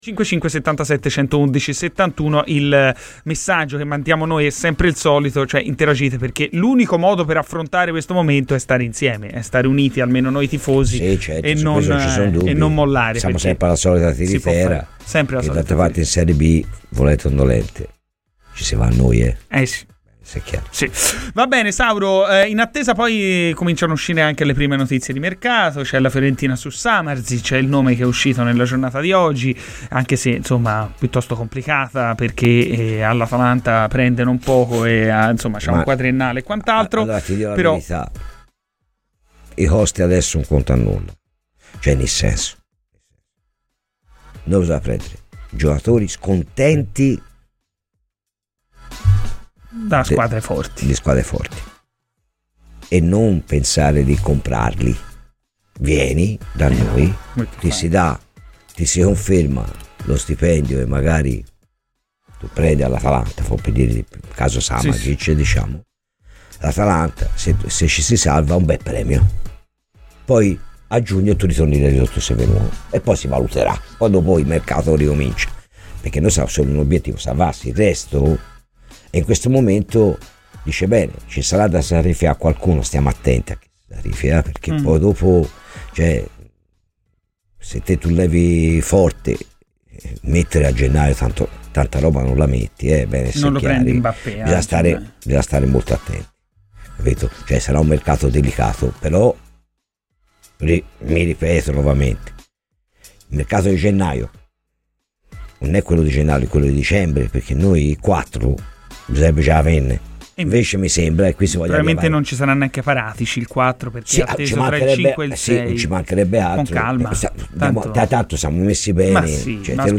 Radio FirenzeViola